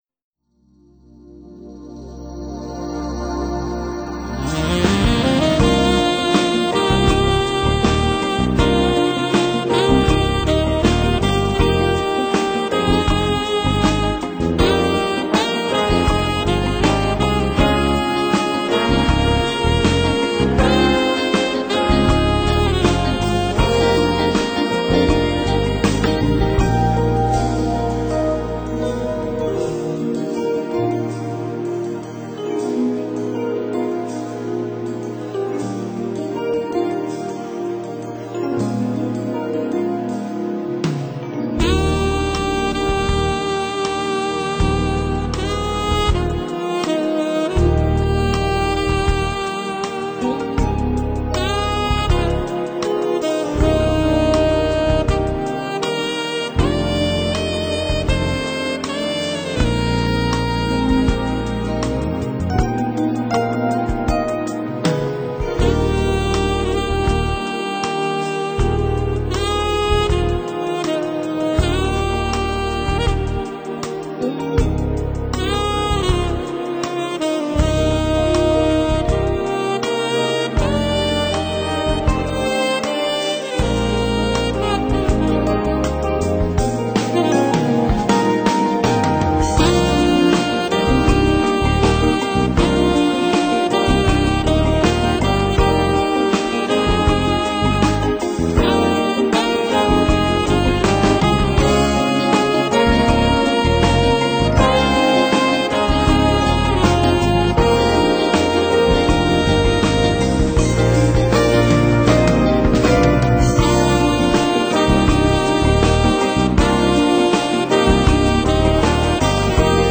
浪漫深情的萨克斯风
交叉运用高、低音萨克斯风的独特音色
柔缓深情的浪漫旋律